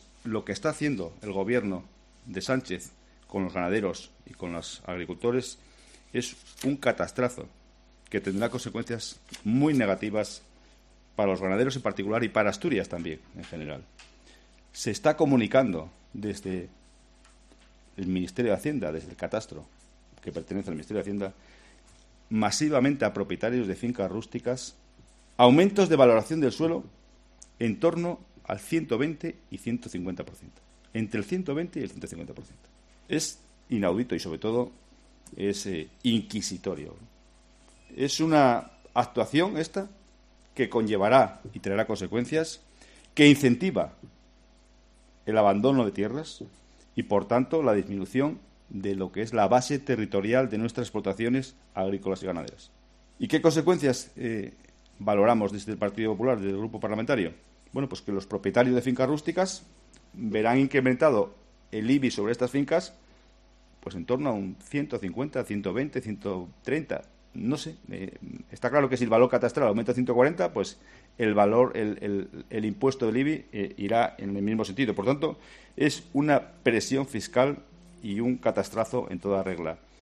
"Es ofensivo lo que está ocurriendo", ha denunciado Luis Venta en una comparecencia ante los periodistas en la Junta General del Principado.